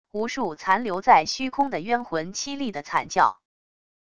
无数残留在虚空的冤魂凄厉的惨叫wav音频